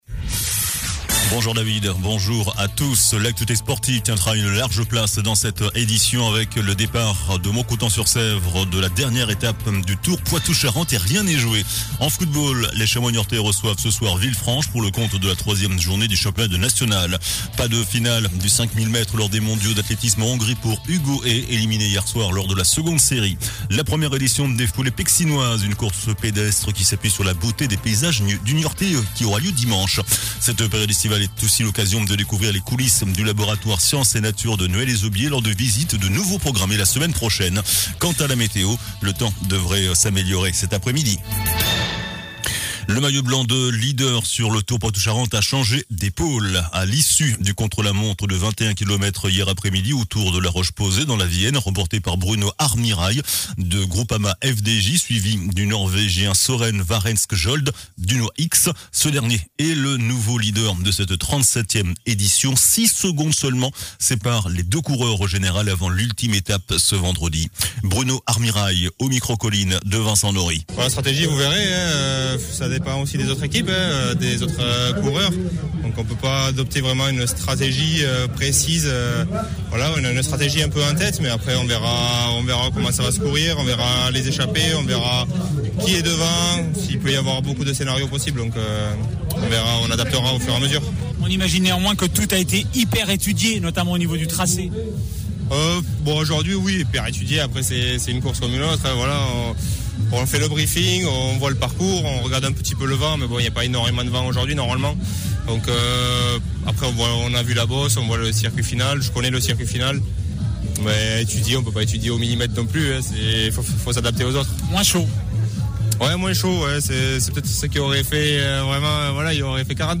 JOURNAL DU VENDREDI 25 AOÛT ( MIDI )